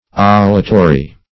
Olitory \Ol"i*to*ry\, a. [L. olitorius belonging to a kitchen